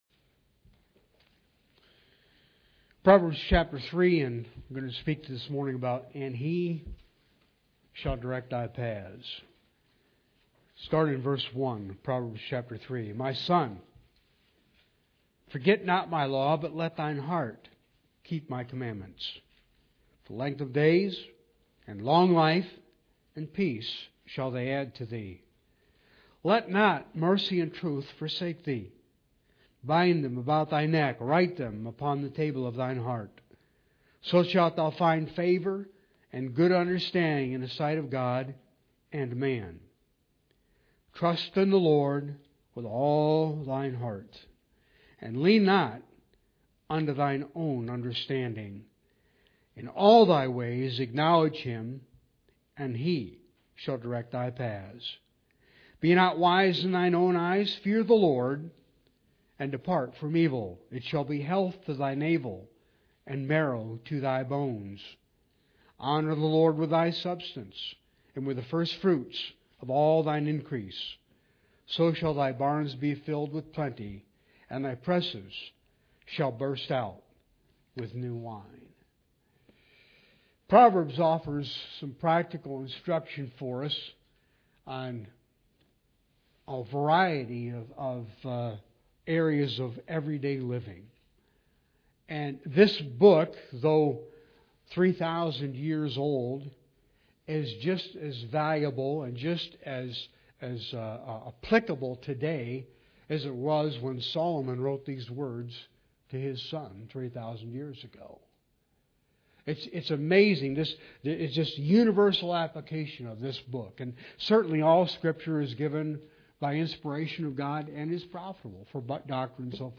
Proverbs 3:1-10 Service Type: Sunday Worship Bible Text